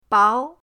bao2.mp3